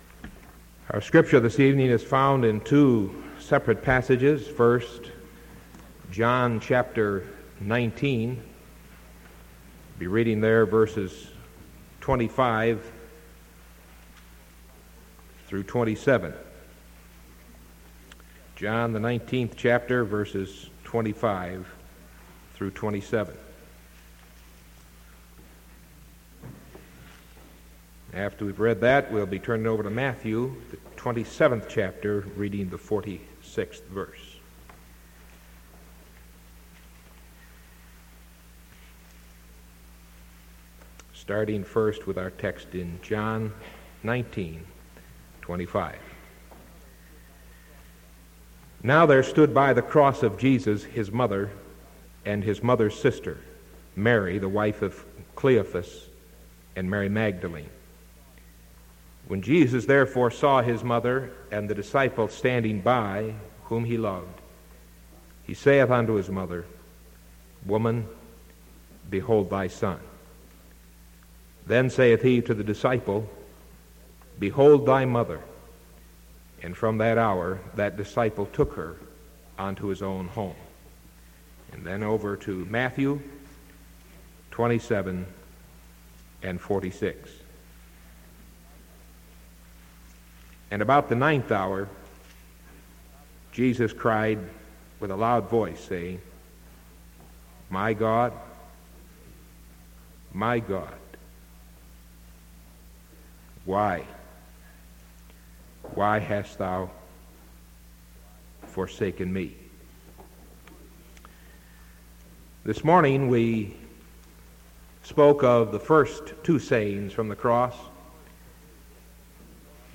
Sermon March 9th 1975 PM